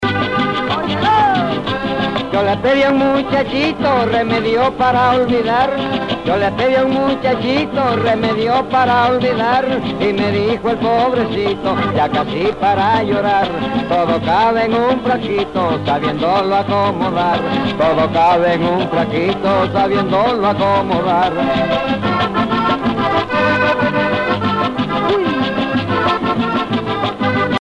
danse : merengue
Pièce musicale éditée